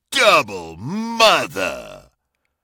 Share and spam your favorite Halo Infinite multiplayer sound effects and voice lines by Jeff Steitzer. Classic multiplayer announcer sounds from the Halo series.